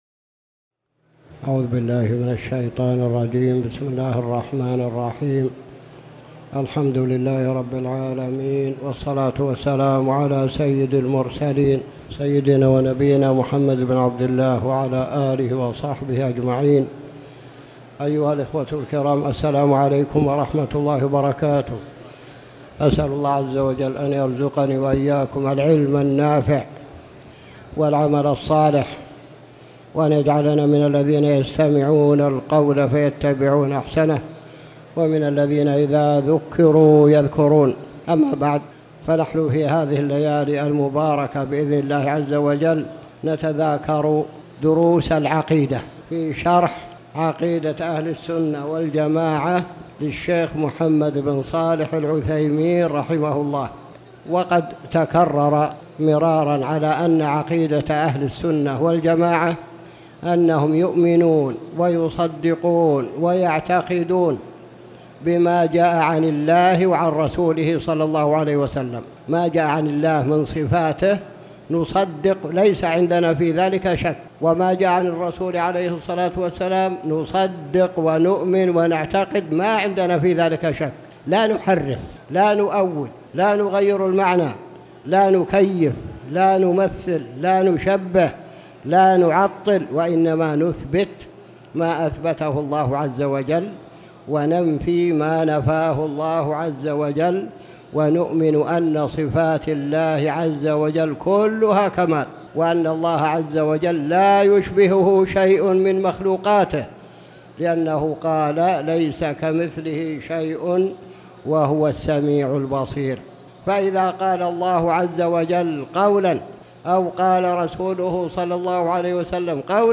تاريخ النشر ٤ صفر ١٤٣٩ هـ المكان: المسجد الحرام الشيخ